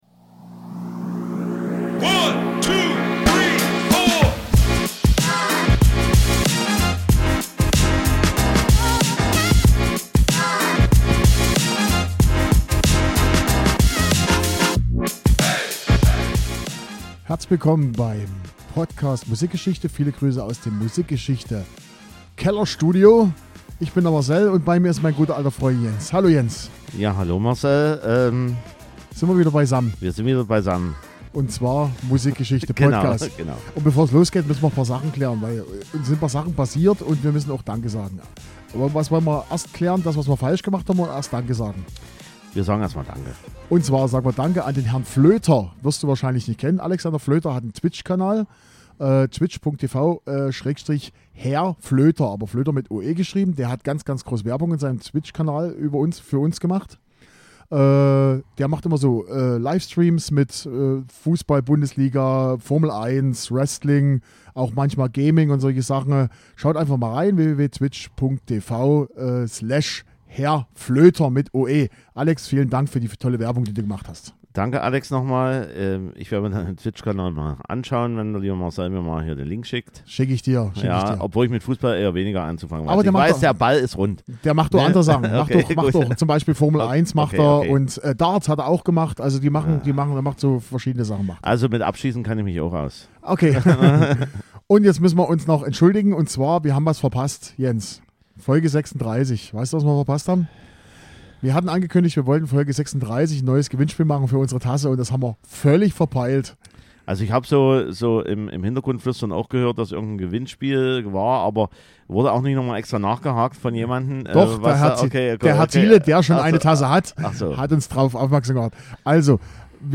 krassen Alternative-Rock